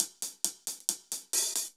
Index of /musicradar/ultimate-hihat-samples/135bpm
UHH_AcoustiHatA_135-03.wav